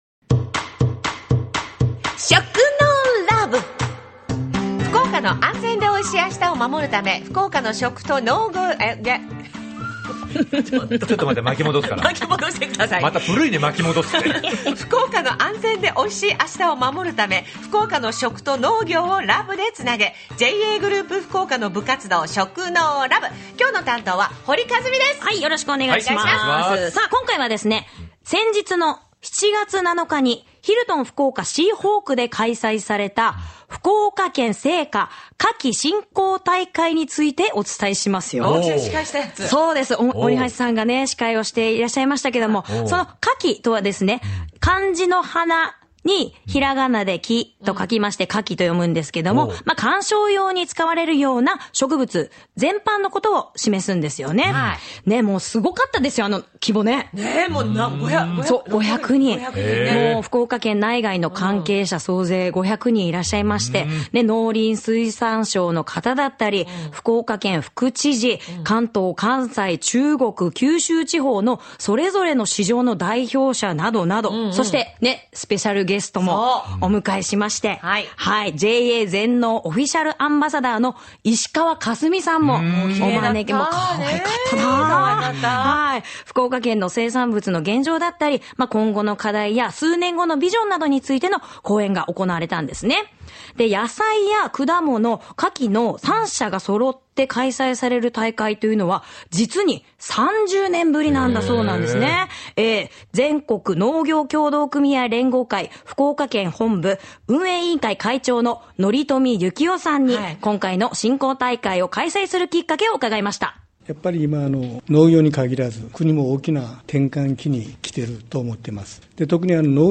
７月７日にヒルトン福岡シーホークで開催された「福岡県青果・花き振興大会」にお邪魔しました。